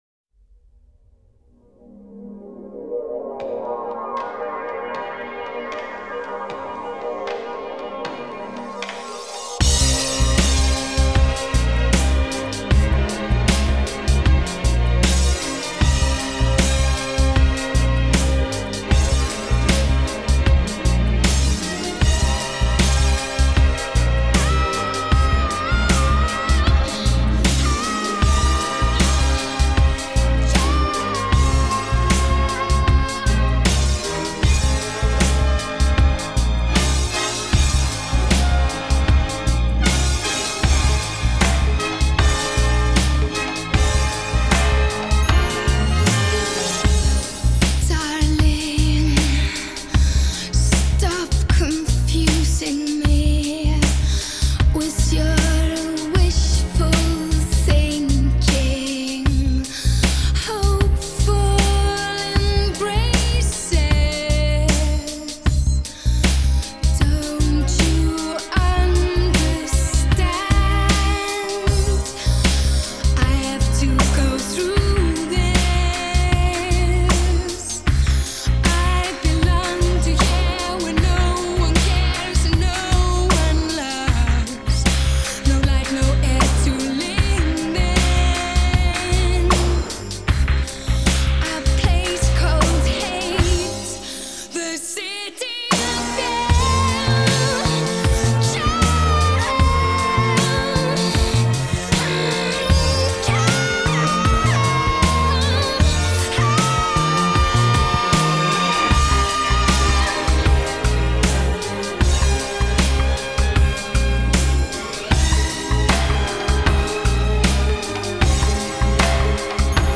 Жанр: alternative, art pop, electronic pop, house